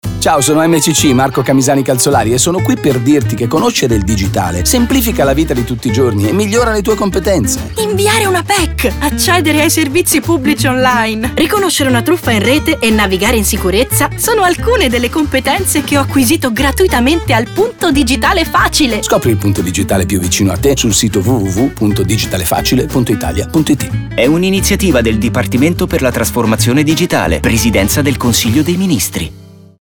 Lo spot radio
punto-digitale-facile-spot-radio.mp3